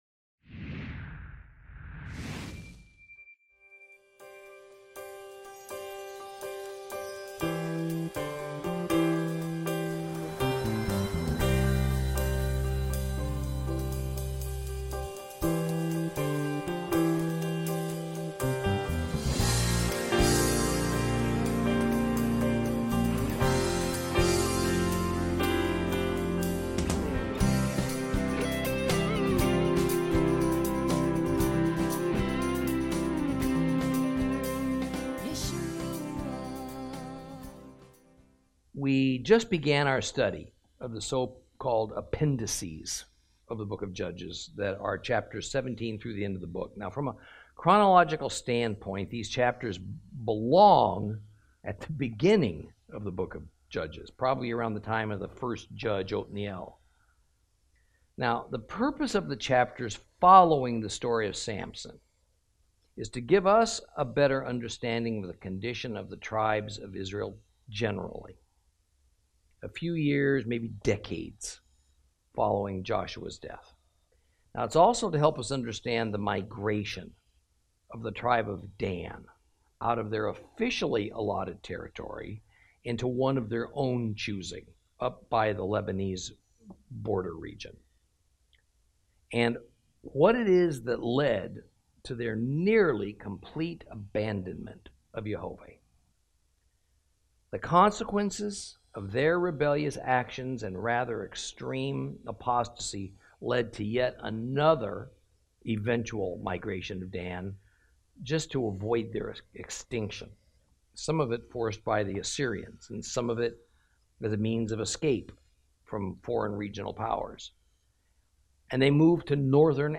Lesson 25 Ch17 Ch18 - Torah Class